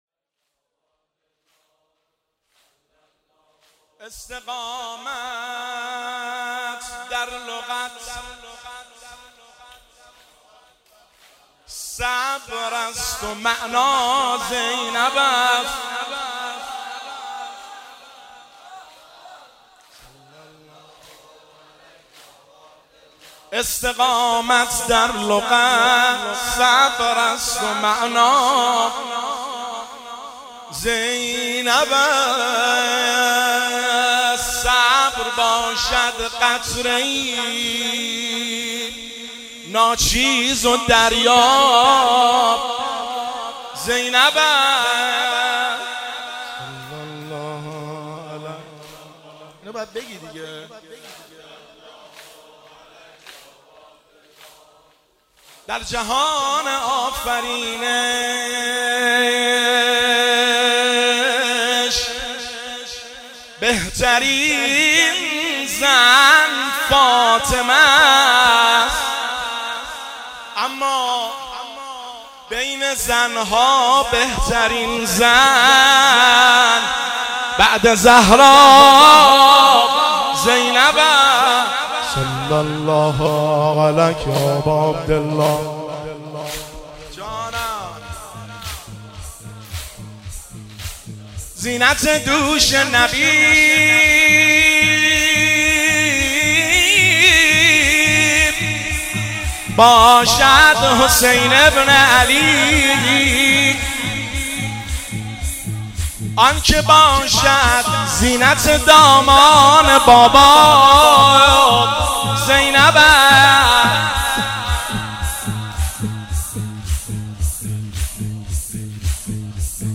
• زمزمه و شعرخوانی – شهادت امام جعفر صادق (ع) 1402